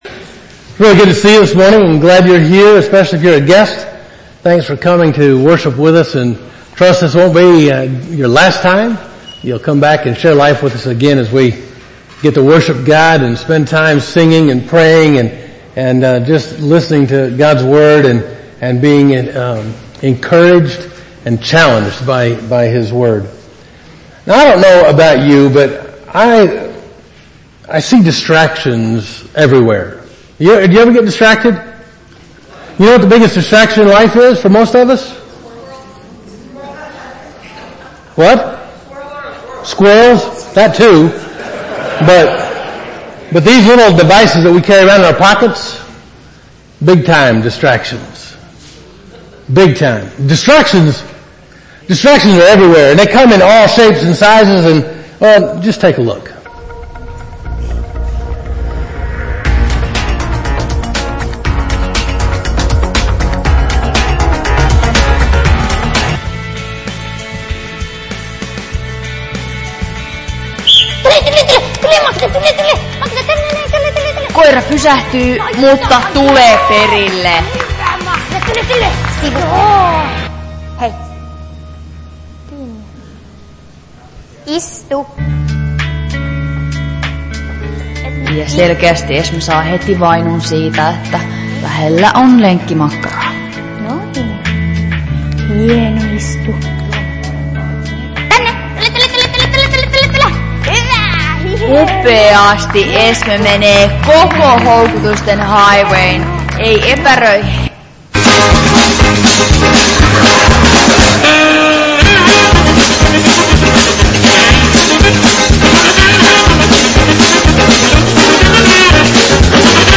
Covenant United Methodist Church Sermons